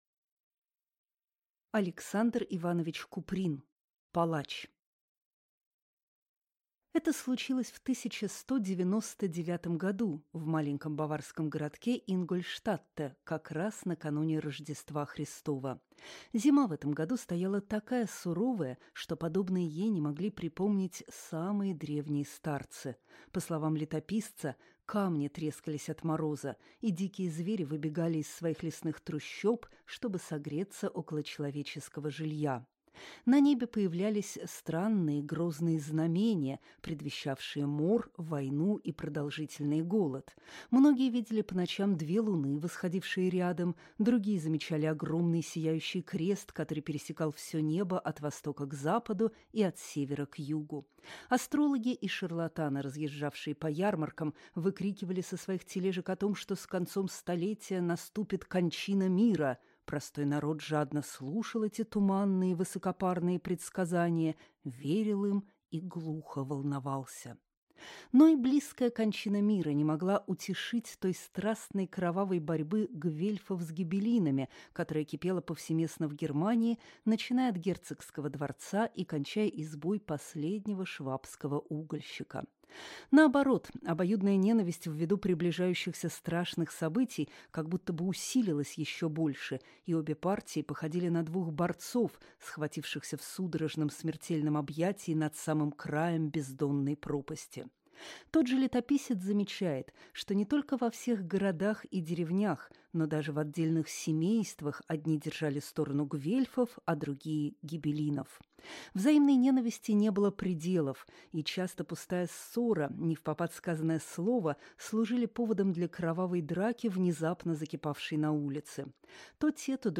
Скачать, слушать онлайн аудиокнигу Палач